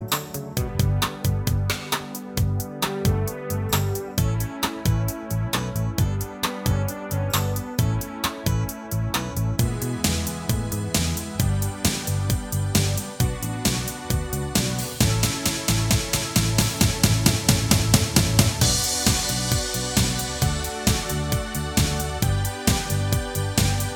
No Guitars Pop (1980s) 4:12 Buy £1.50